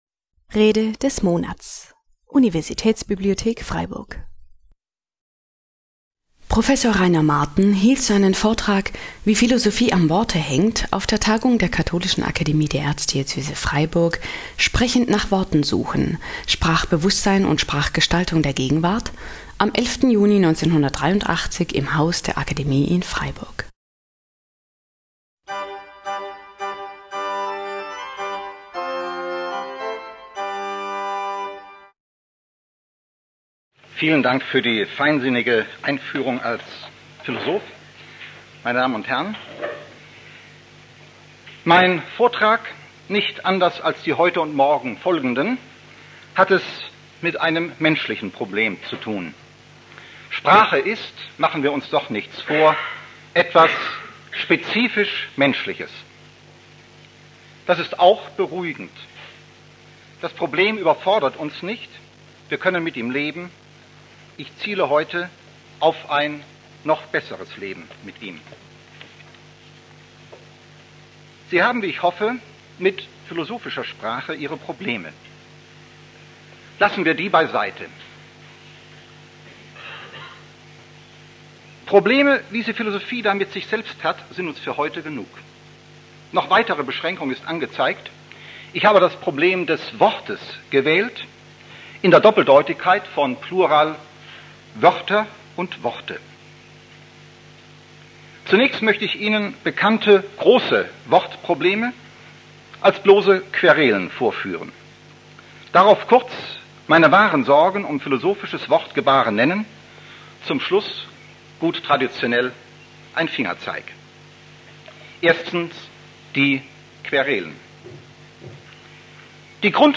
Wie Philosophie am Worte hängt (1983) - Rede des Monats - Religion und Theologie - Religion und Theologie - Kategorien - Videoportal Universität Freiburg